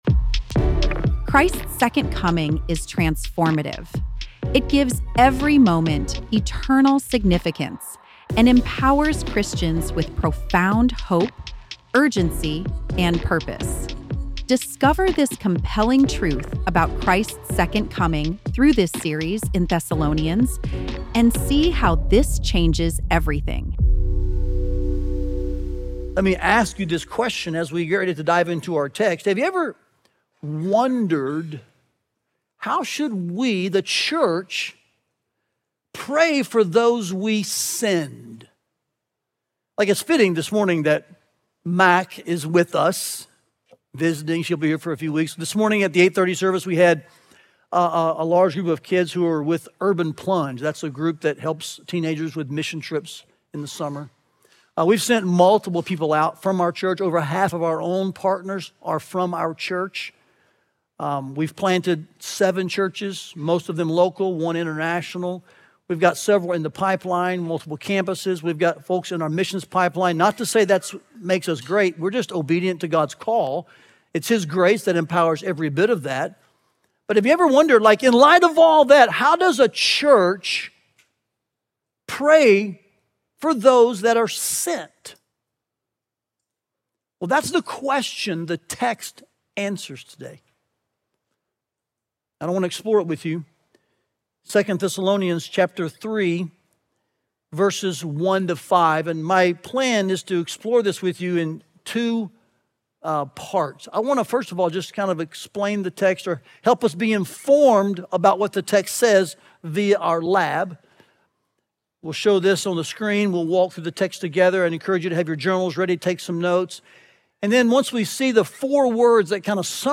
Listen to the latest sermon and learn more about this preaching series here.